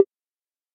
tap3.ogg